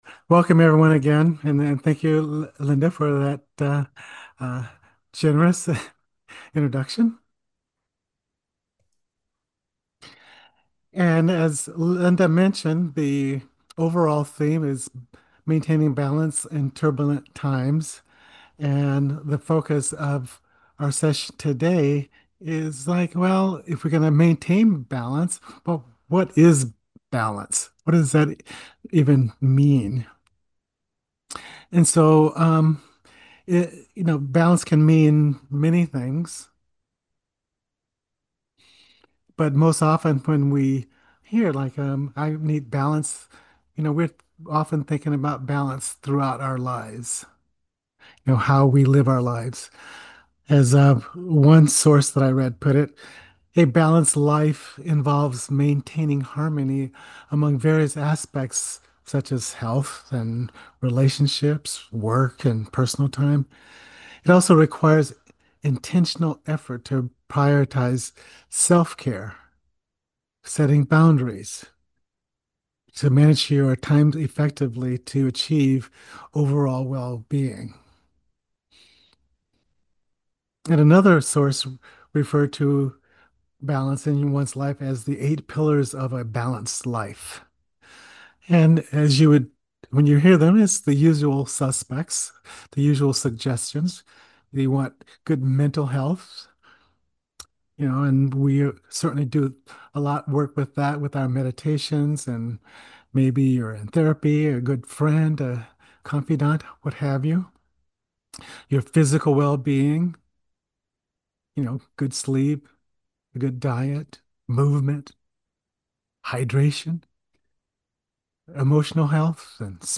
via Zoom